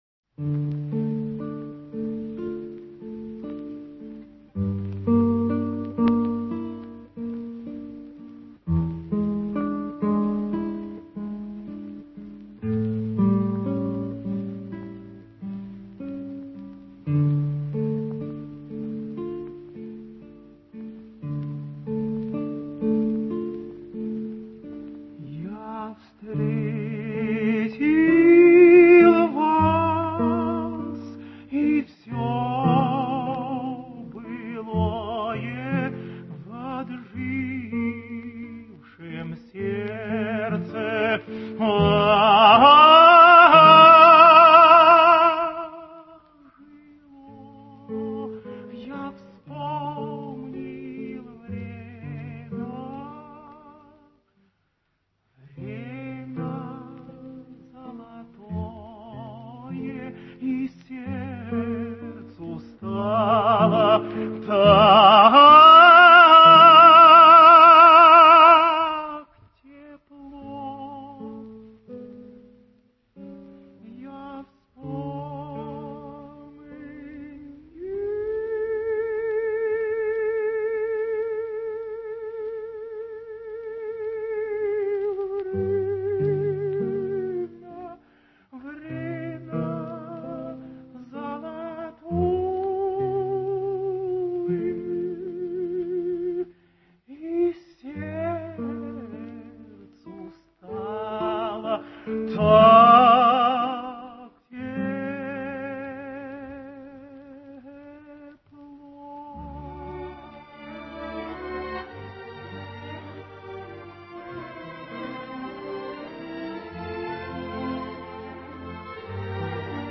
Романс «Я встретил вас…» на стихи Тютчева. Поёт Иван Козловский (скачать)